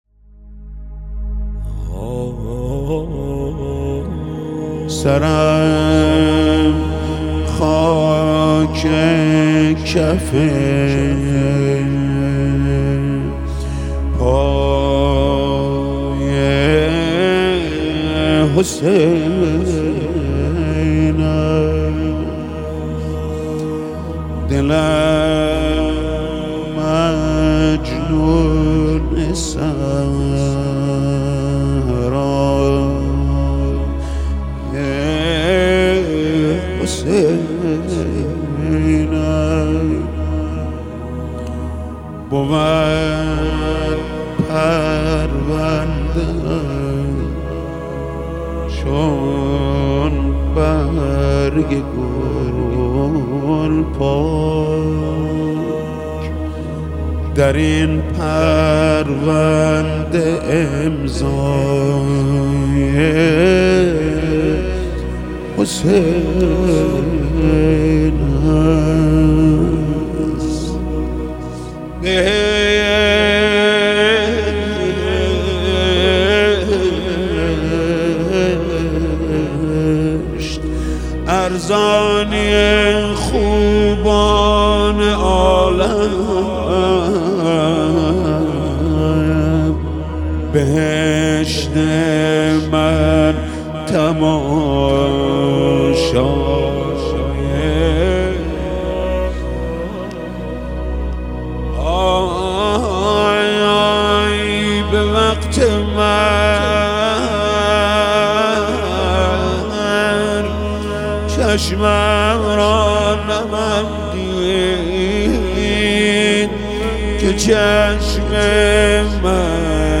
مناجات با امام حسین (ع)